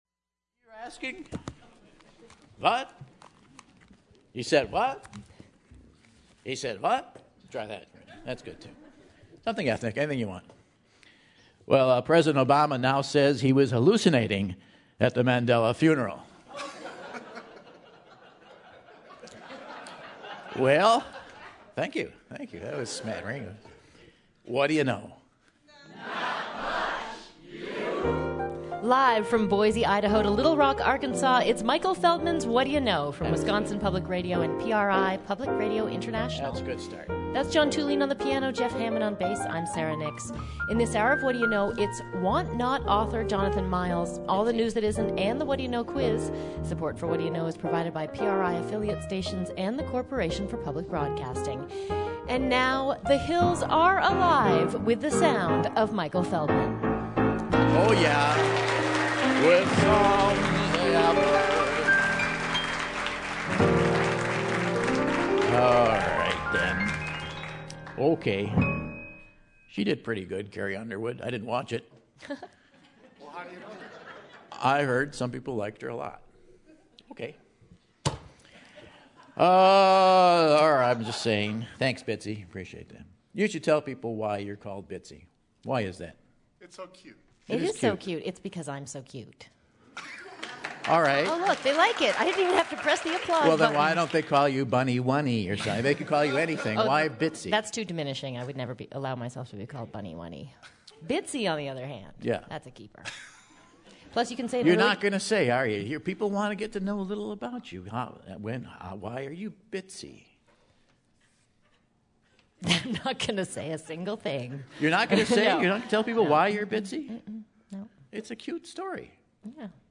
December 14, 2013 - Madison, WI - Monona Terrace | Whad'ya Know?